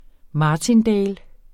Udtale [ ˈmɑːtinˌdεjl ]